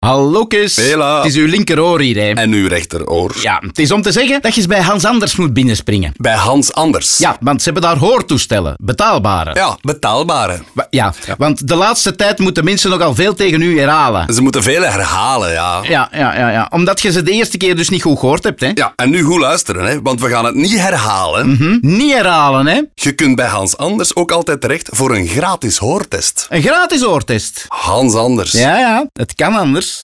Om de awareness rond de hoortoestellen van Hans Anders te verhogen, creëerde mortierbrigade een radioconcept waarbij je eigen oren je aanspreken.